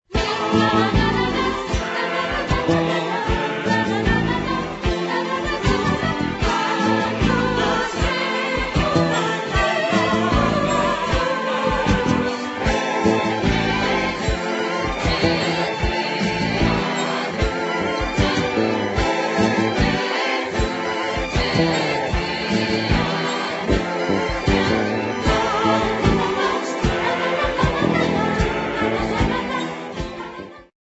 thrilling medium voc.